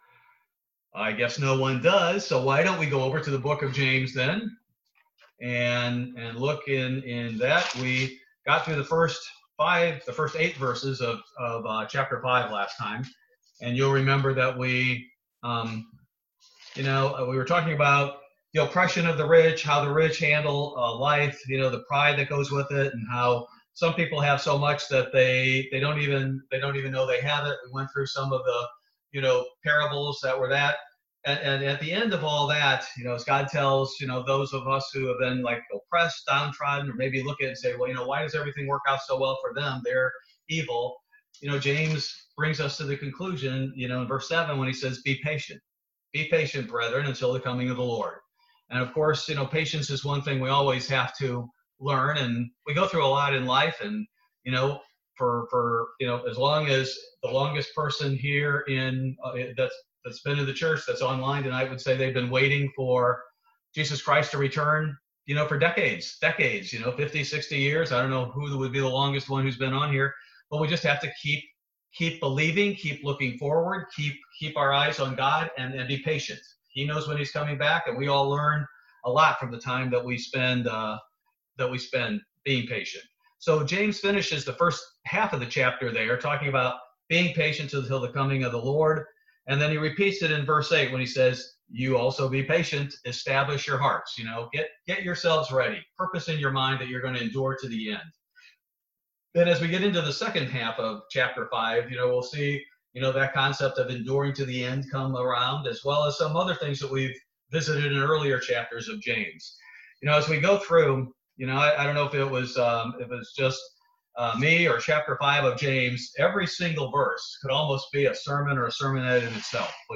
Bible Study June 10, 2020